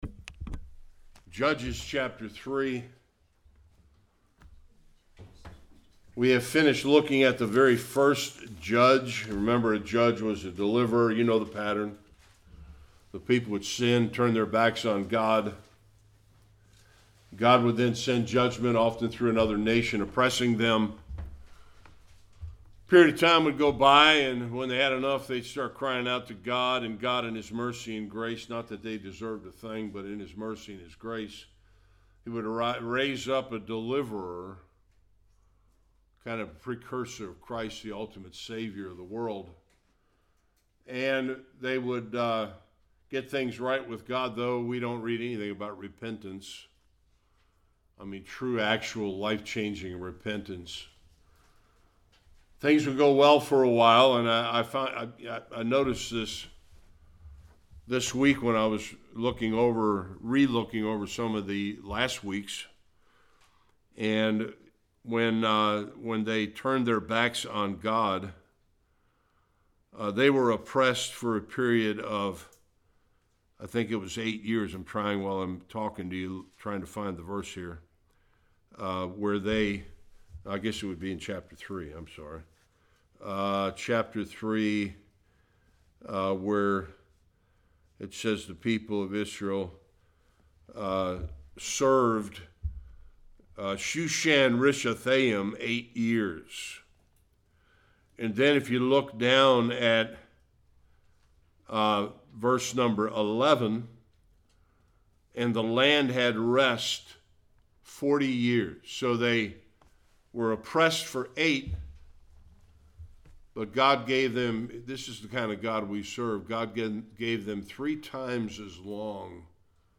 15-31 Service Type: Sunday School The LORD raised up another Judge to deliver Israel from the Moabites